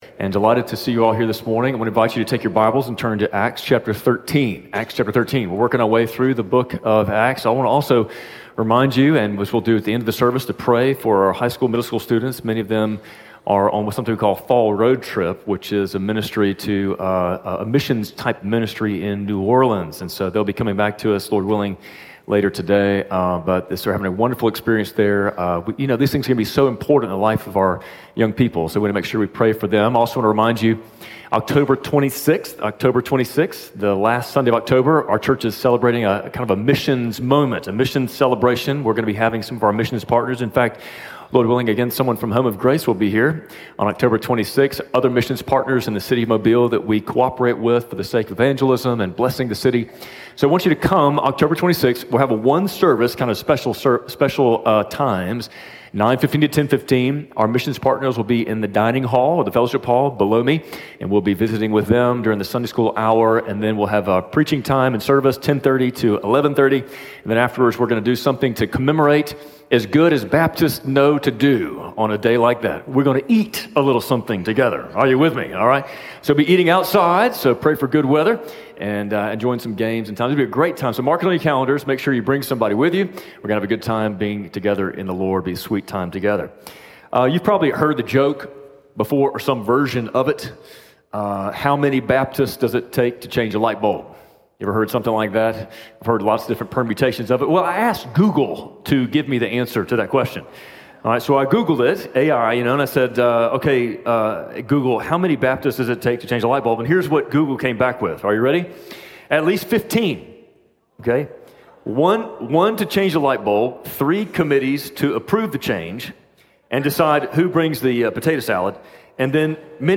Spring Hill Baptist Sunday Sermons (Audio)